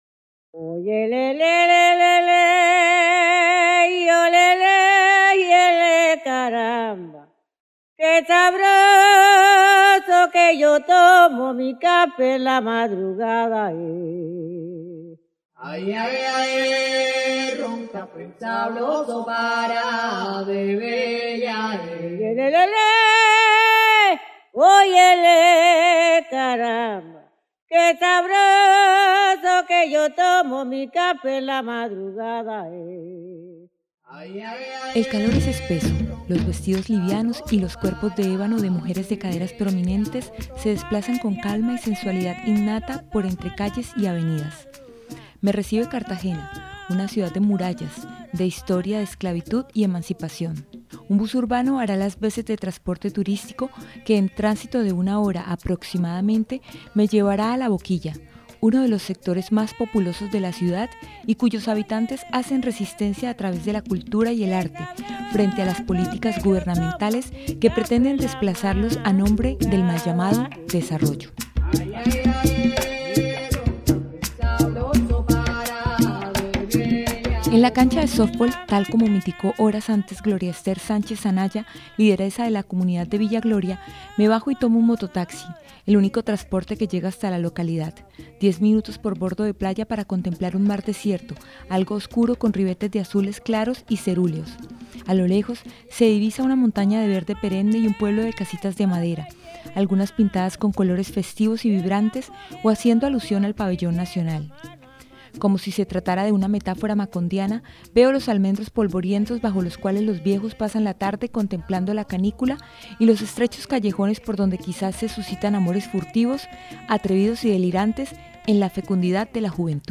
Cantadoras de Guabina y Bullerengue: un relato de Buen Vivir , es un podcast que vincula voces y mundos sonoros de mujeres cantadoras de tres regiones del norte del país. Pretende a través de una travesía entre paisajes y recuerdos, mostrar la importancia que tiene la música para las cantadoras de guabina y bullerengue de tres territorios específicos: La Boquilla- Villa Gloria, San Basilio de Palenque en el departamento de Bolívar y Vélez en Santander.